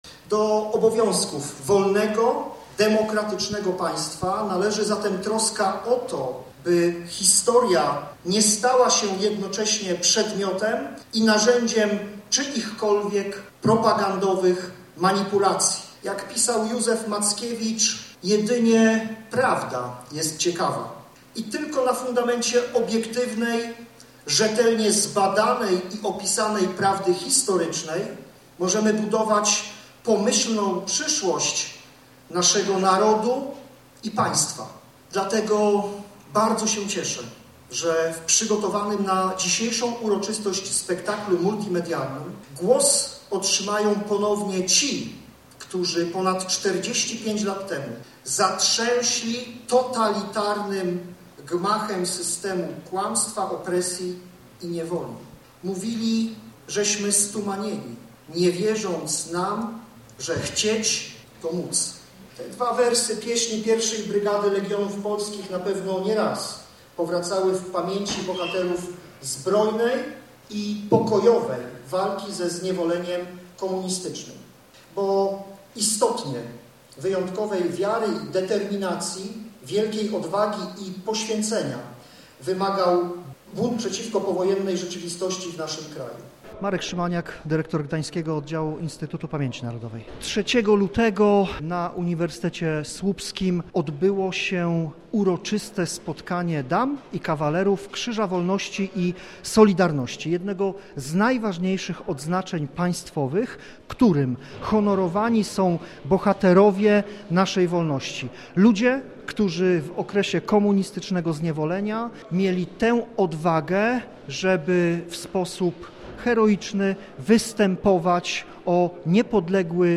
W auli Uniwersytetu Pomorskiego pojawili się parlamentarzyści, samorządowcy oraz działacze Solidarności.
Koncert_Solidarnosci_Dlugi.mp3